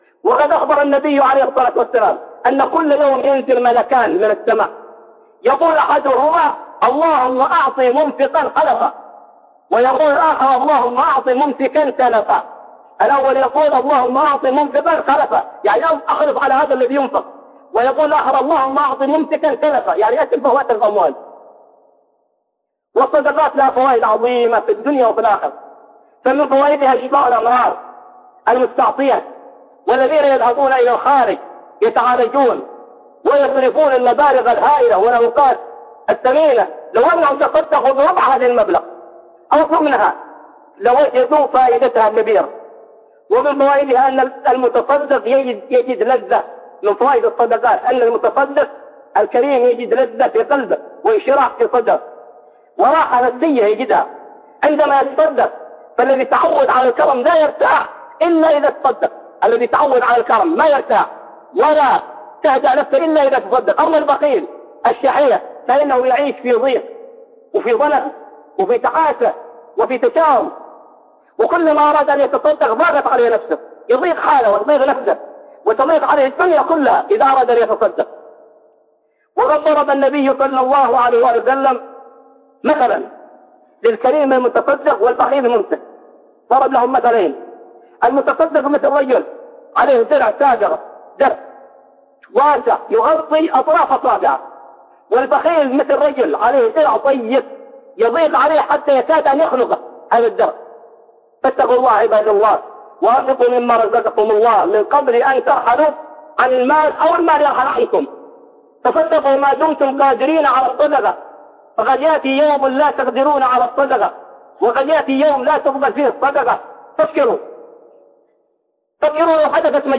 خطب ومحاضرات أخرى